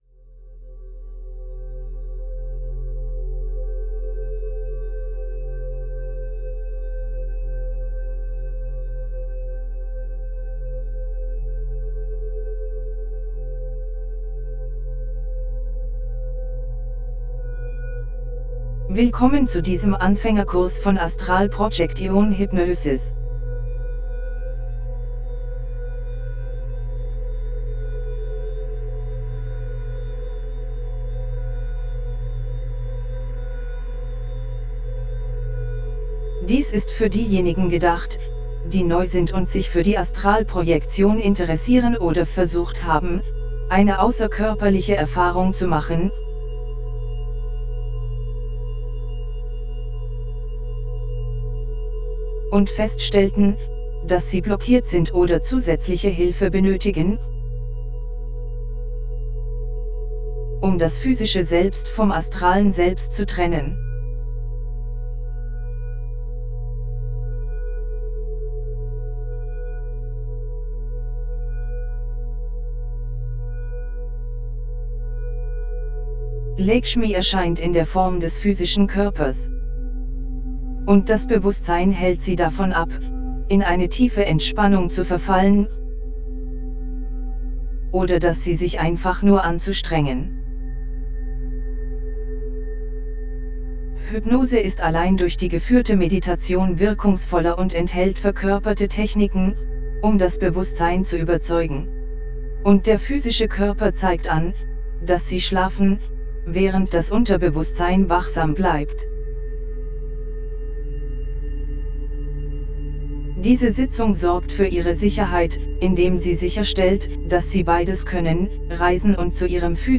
OBE1BeginnersAstralProjectionHypnosisMeditationDE.mp3